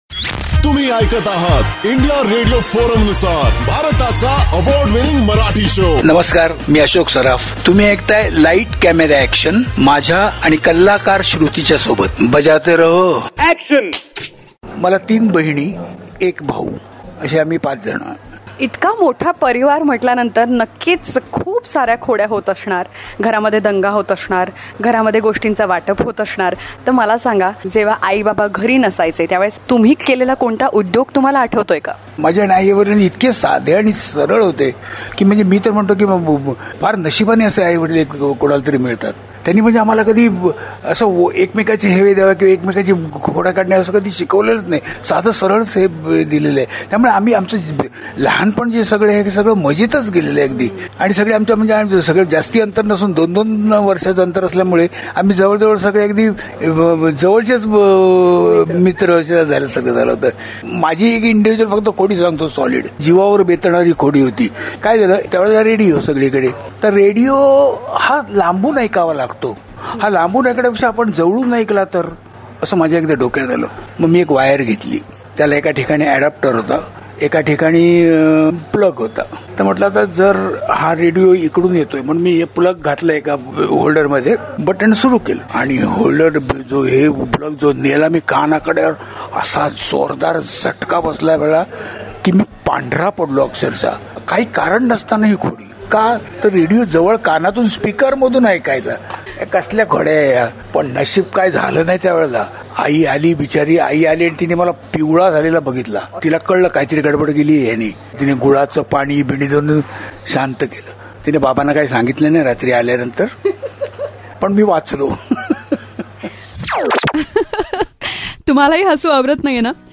VETRAN ACTOR ASHOK SARAF TALKS ABOUT THE MISCHIEF'S HE WOULD BE UPTO AS A CHILD